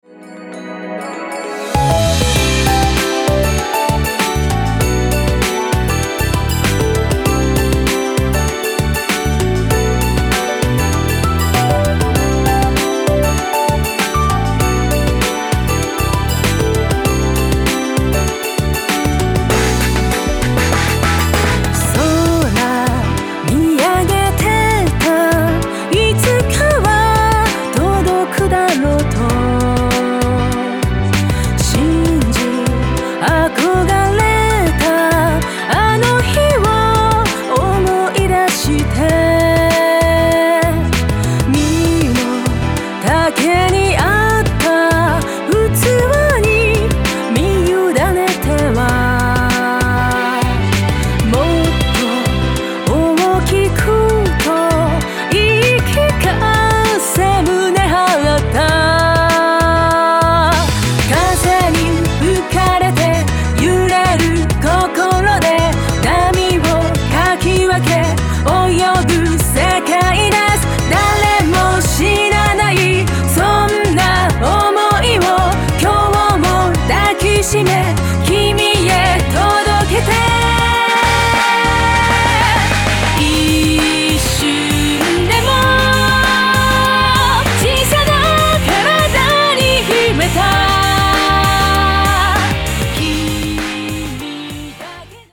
クロスフェードデモ
フルボーカルアレンジアルバムがここに完成いたしました！